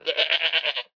goat2.wav